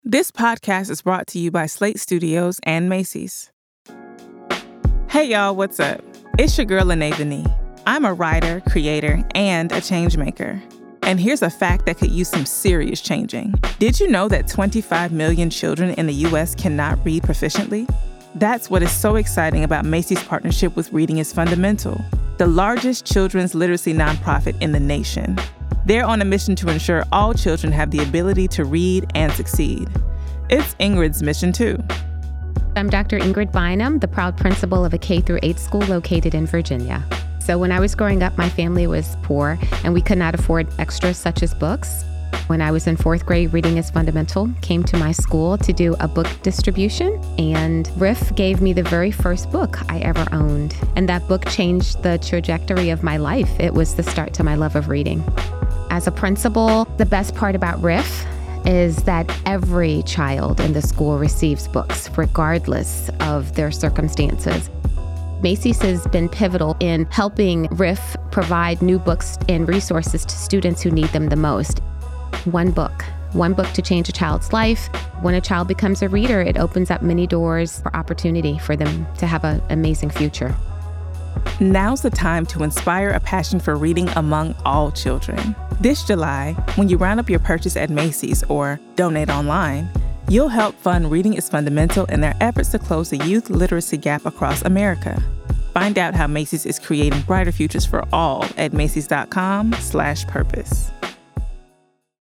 supporter and program implementer in this brief interview with Slate Studios.